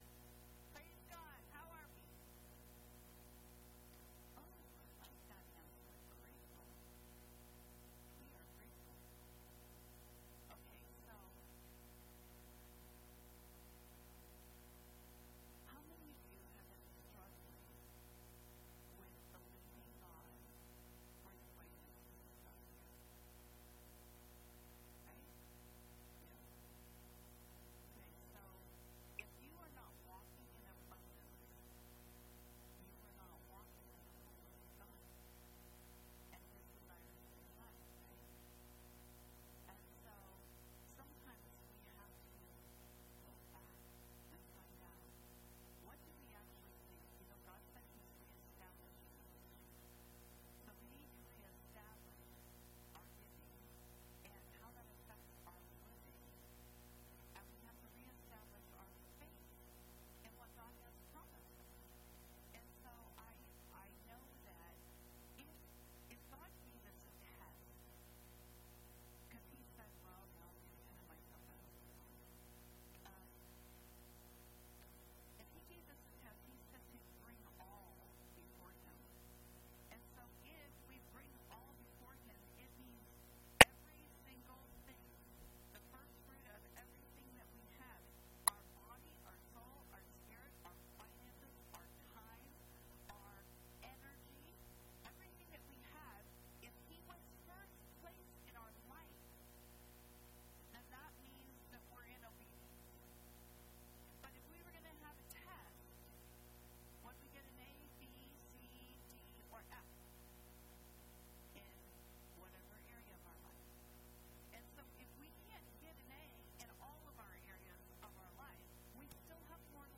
Listen to Liberty's Past Services